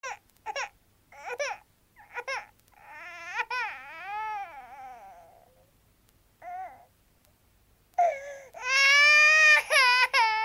children-cry_21174.mp3